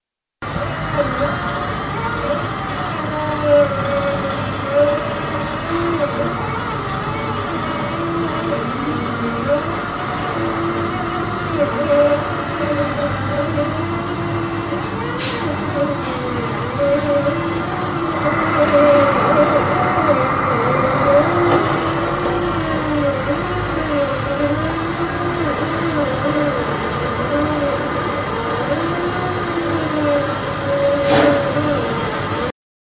Pava（用于冷却水的液体） " Pava calentando
描述：一个水锅看起来像它会爆炸。
Tag: 恐怖 terrifyin克 悬念 压力 焦虑 金属 险恶